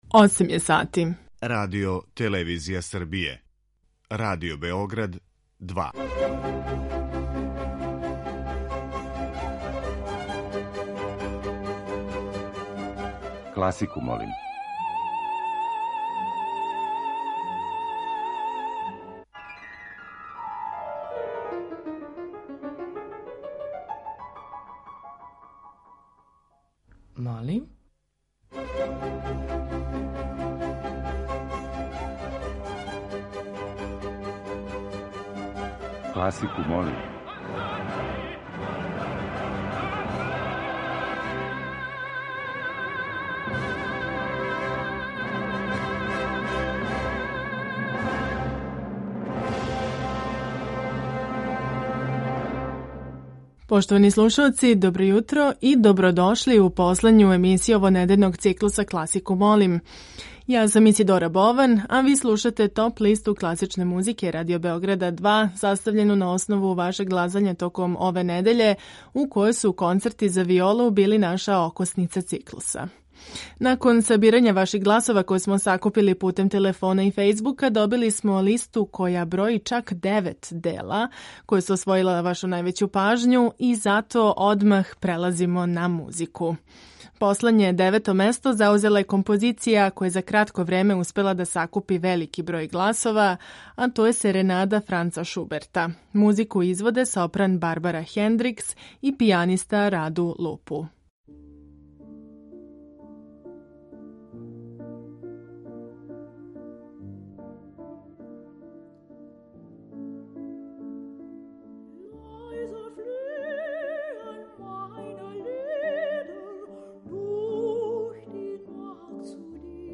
Тема циклуса носи наслов 'Концерти за виолу'.